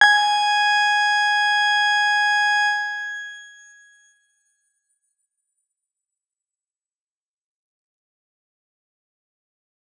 X_Grain-G#5-pp.wav